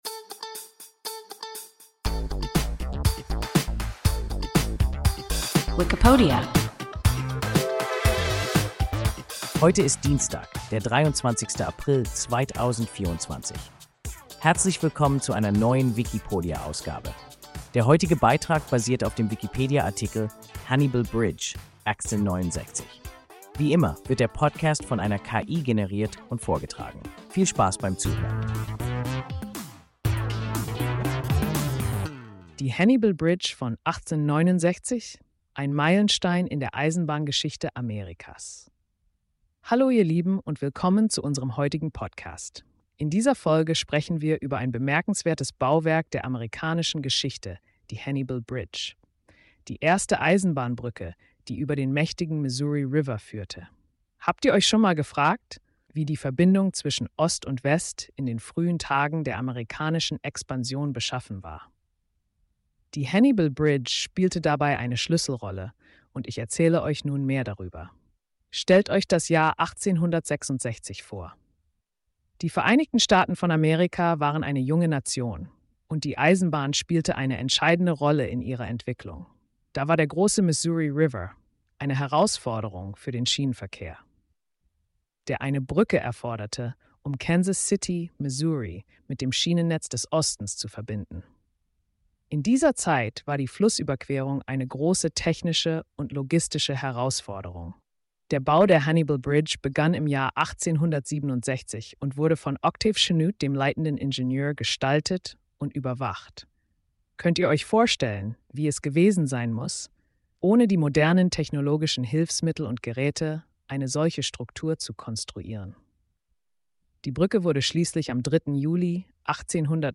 Hannibal Bridge (1869) – WIKIPODIA – ein KI Podcast